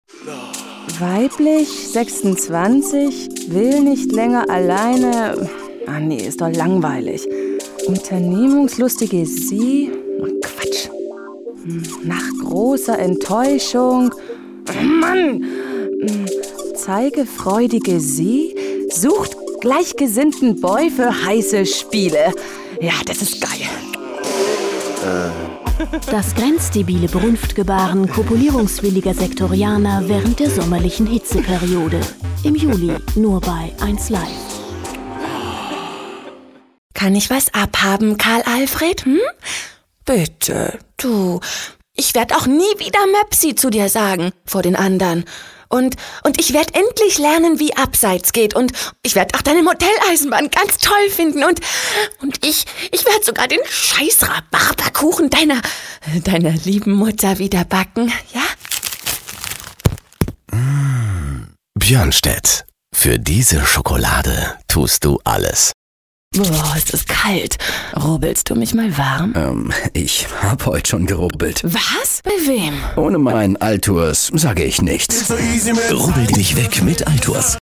deutsche Profi Sprecherin mit einer vielseitigen Stimme: freundlich warm - szenemässig cool - sinnlich lasziv . Spezialität: Dialekte und Akzente
Sprechprobe: Industrie (Muttersprache):